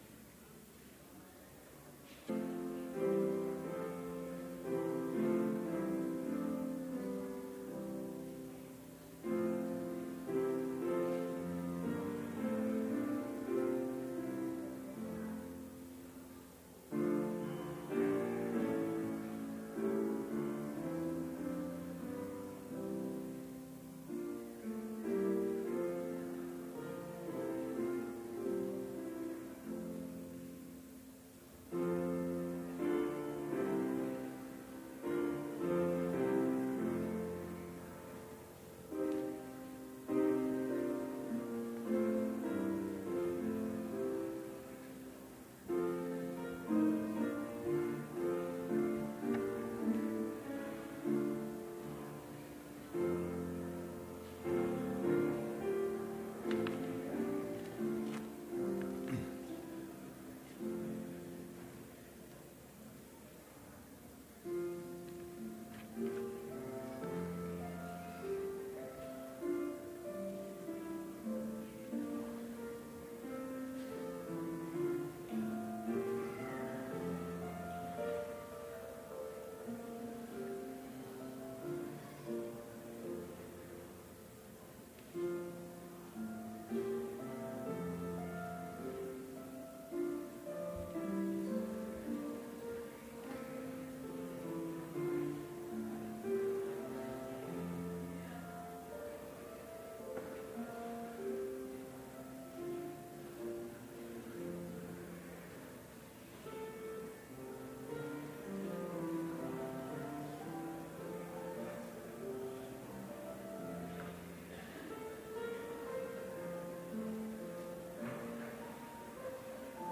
Complete service audio for Chapel - December 14, 2018